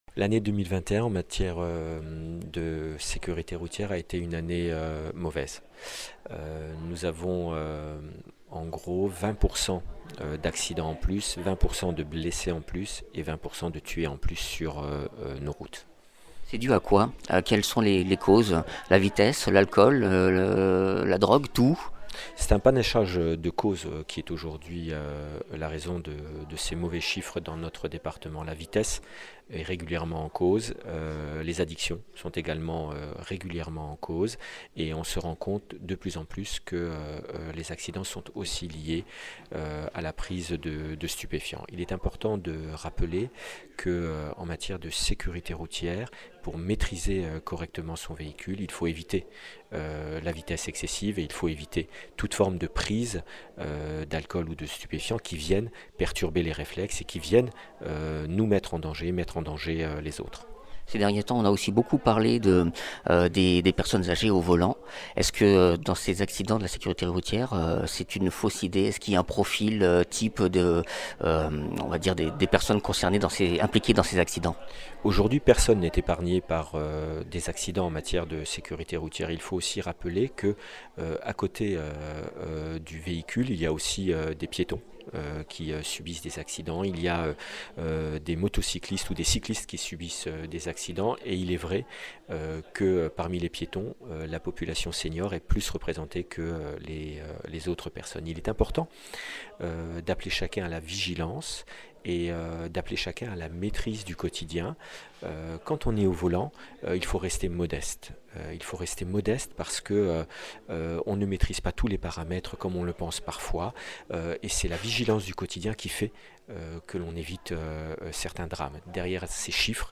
Interviews
Invité(s) : Michel Prosic, Préfet du lot